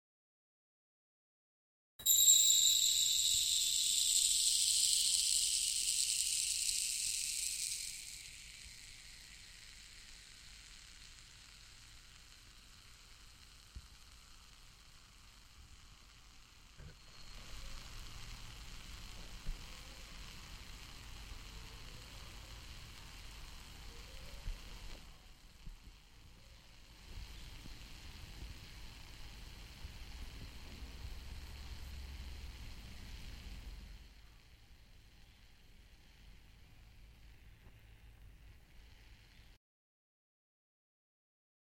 На этой странице собраны уникальные звуки песочных часов — от мягкого пересыпания песка до четких щелчков при перевороте.
Шуршание песка из песочных часов